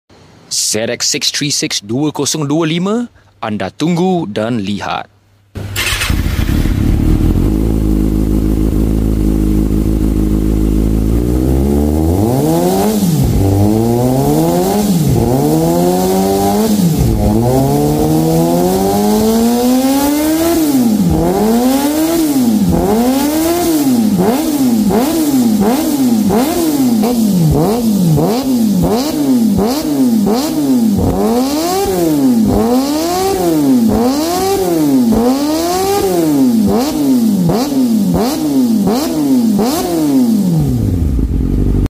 enjoy dulu pure 4 inline 636cc sound!